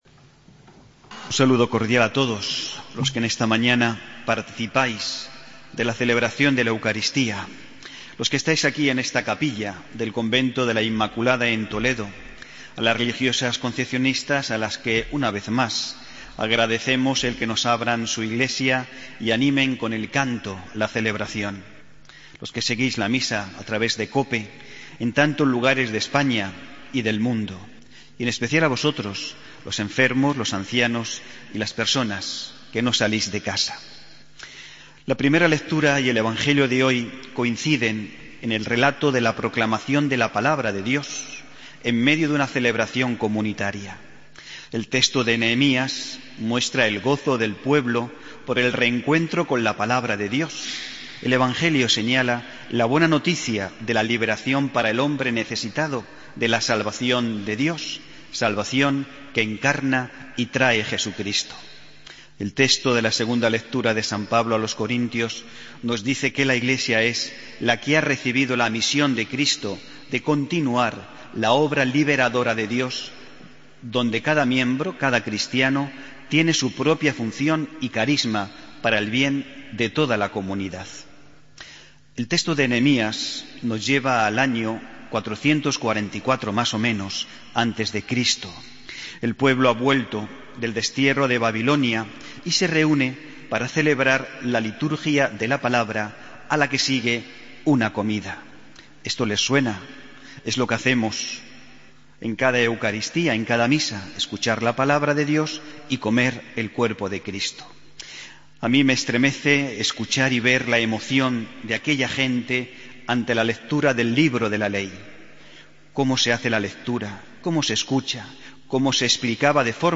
Homilía del domingo 24 de enero de 2016